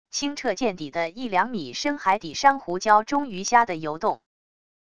清澈见底的一两米深海底珊瑚礁中鱼虾的游动wav音频